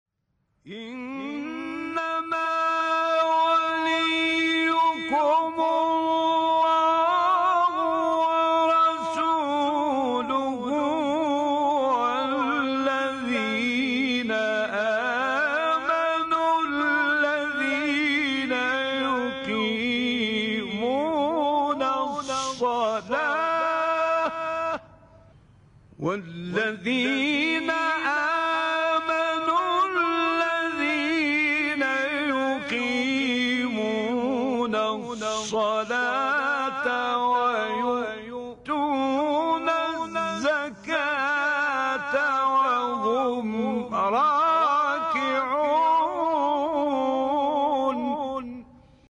تلاوت آیه ولایت با صوت راغب مصطفی غلوش
برچسب ها: مقاطع صوتی از تلاوت ، آیه ولایت ، فرازی از تلاوت آیه ولایت ، تلاوت آیه ولایت از قاری مصری ، تلاوت آیه ولایت از قاری ایرانی